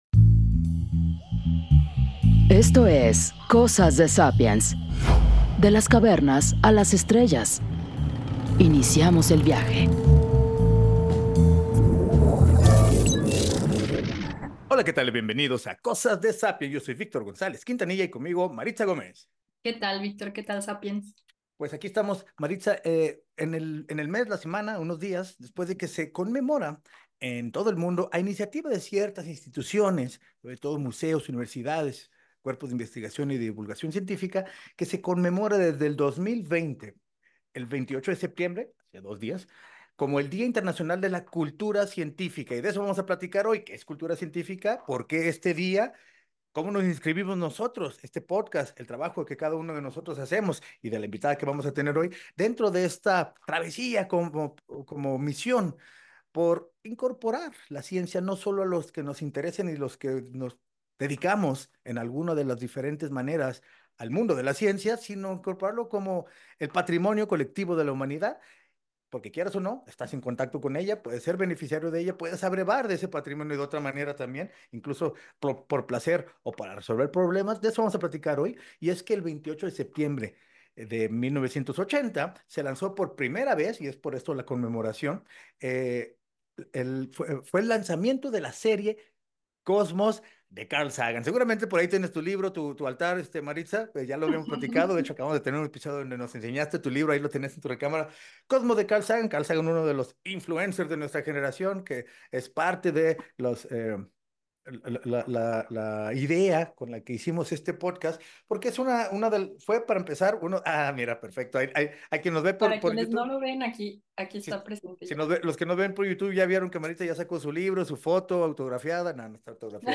Pero, ¿Qué nos falta para que esto sea una realidad para todos? Platicamos con la doctora